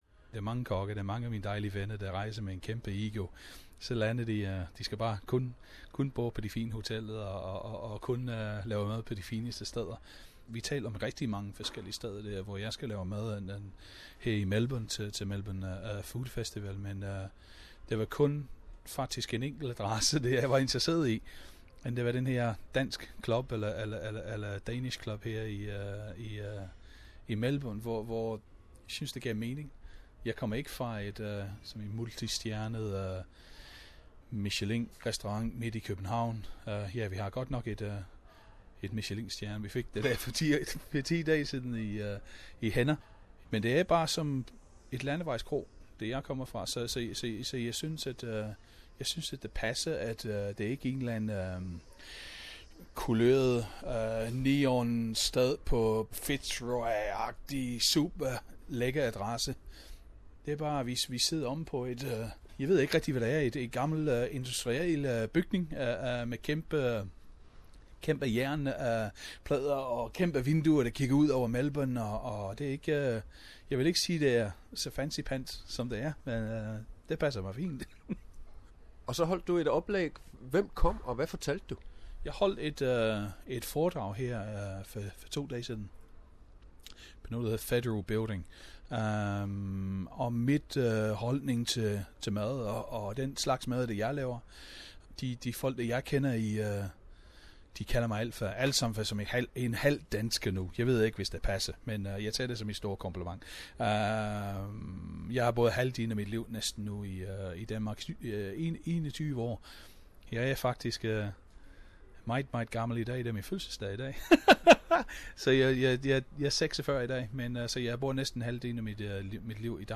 Interview (in Danish language) with master chef Paul Cunningham from Korsør in Denmark
Paul Cunningham at Denmark House in Melbourne Source: SBS